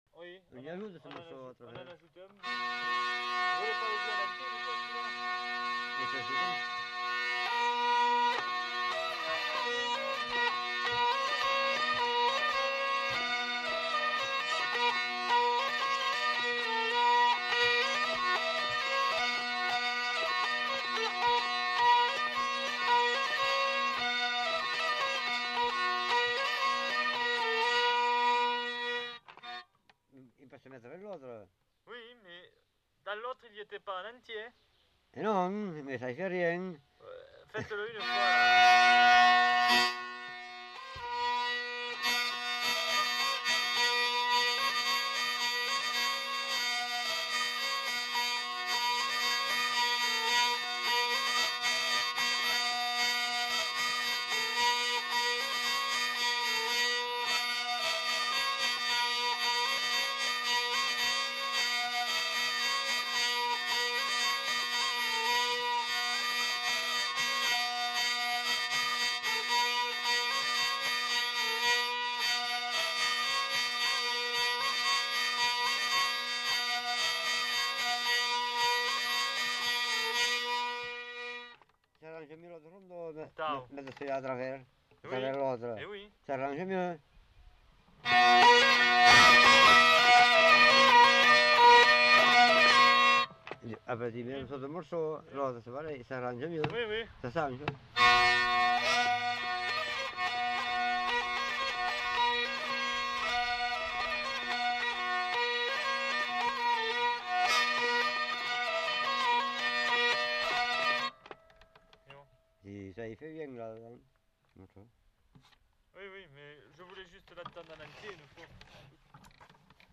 Aire culturelle : Gabardan
Genre : morceau instrumental
Instrument de musique : vielle à roue
Danse : rondeau